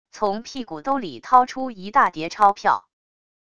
从屁股兜里掏出一大叠钞票wav音频